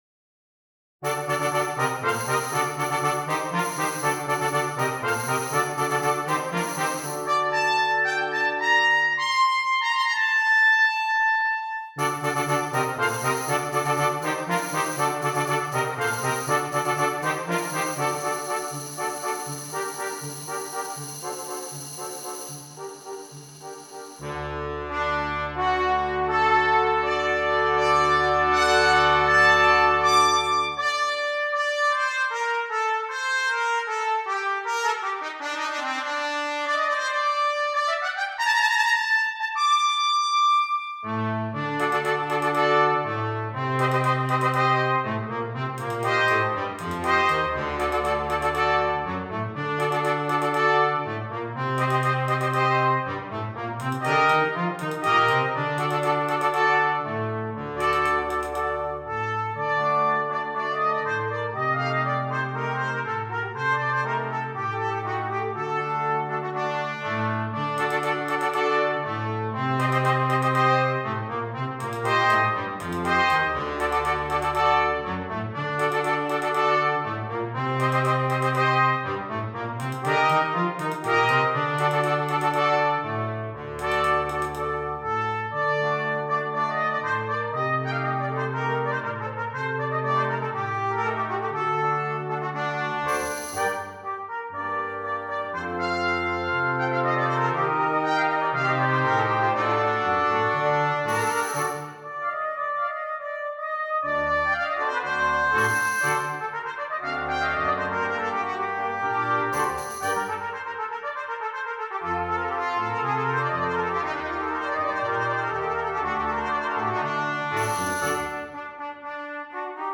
Brass Quintet and Solo Trumpet
Traditional
traditional bullfighting song
features a short cadenza and some requisite high notes